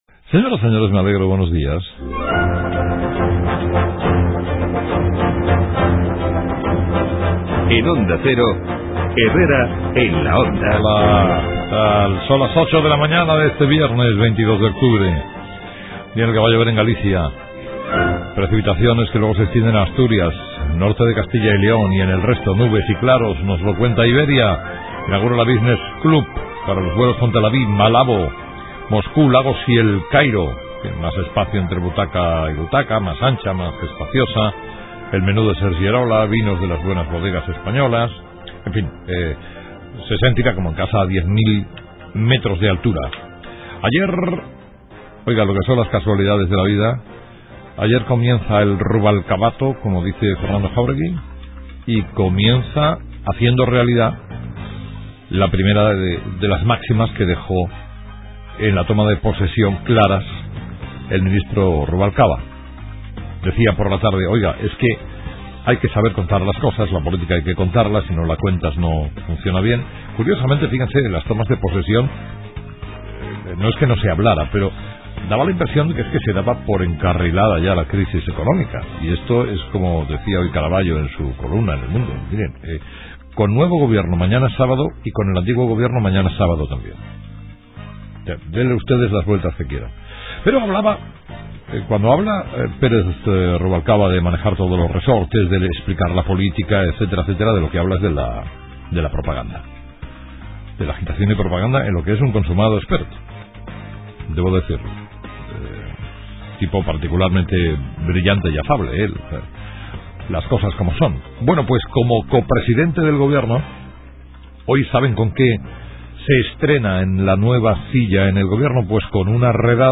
Entrevista con Mª Luz Rodríguez
La secretaria de Estado de Empleo confía en Herrera en la onda en que gobierno y sindicatos alcancen un acuerdo sobre la reforma de las pensiones, a 72 horas de que finalice la fecha límite para su aprobación.